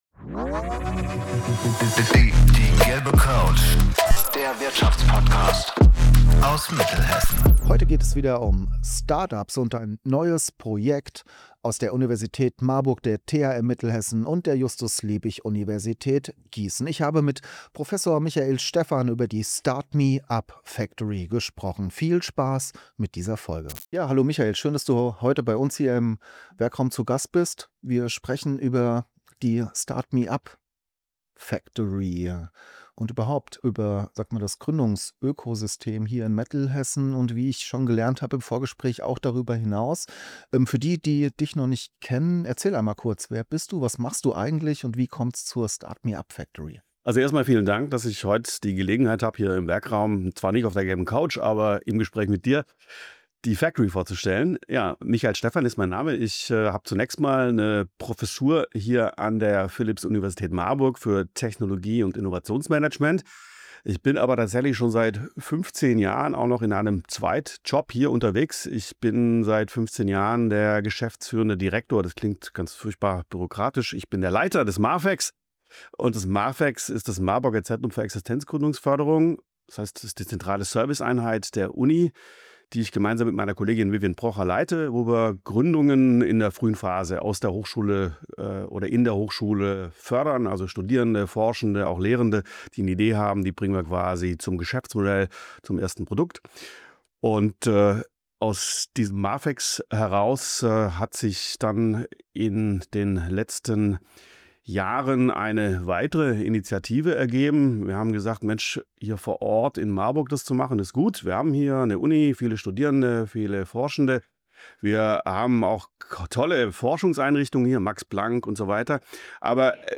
Wir beleuchten die Rolle der Hochschulen, die neue Public Private Partnership Struktur der Factory und die Zukunft von Start-ups im Bereich Sustainable Life. Ein Gespräch über regionale Stärke mit internationaler Ausstrahlung, ambitionierte Innovationsförderung und die Chancen für Unternehmen, sich aktiv zu beteiligen.